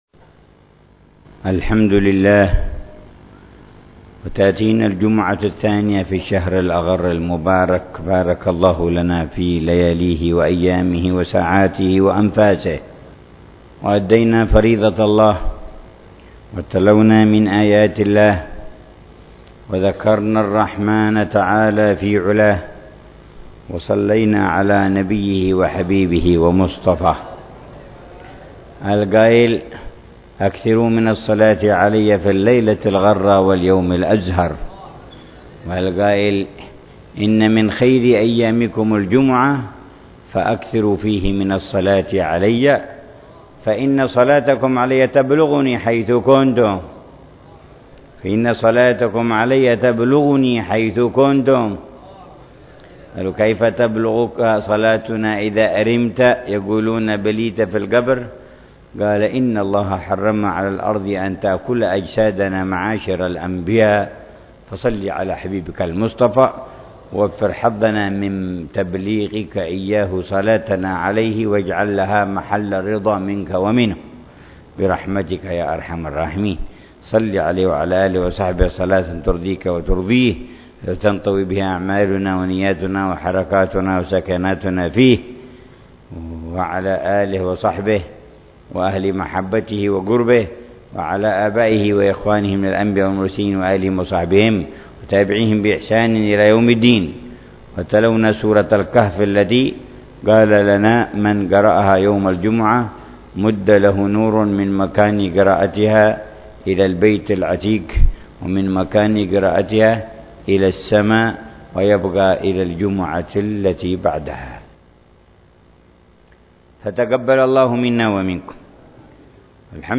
يواصل الحبيب عمر بن حفيظ تفسير قصار السور، موضحا معاني الكلمات ومدلولاتها والدروس المستفادة من الآيات الكريمة، ضمن دروسه الرمضانية في تفسير جز